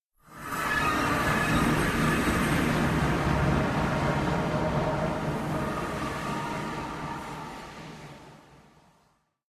MinecraftConsoles / Minecraft.Client / Windows64Media / Sound / Minecraft / portal / travel.ogg
travel.ogg